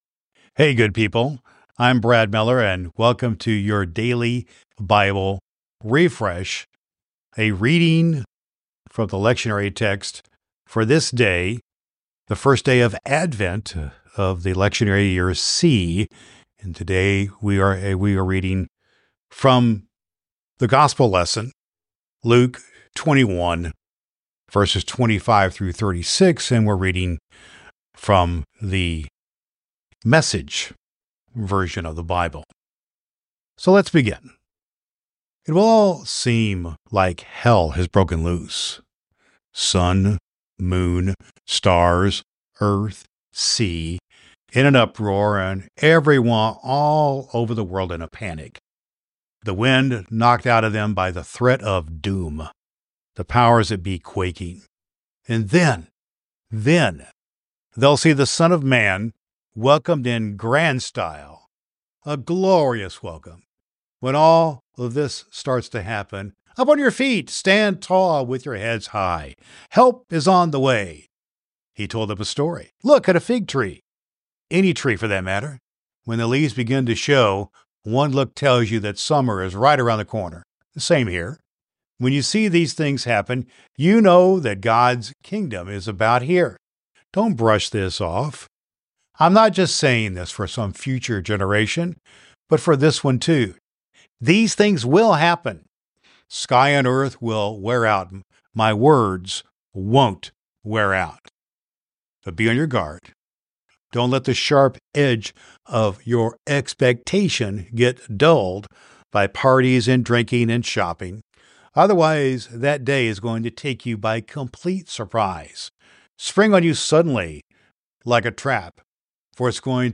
In this episode, we delve into the first day of Advent of lectionary year C with a profound reading from the Gospel of Luke 21:25-36, using the message version of the Bible.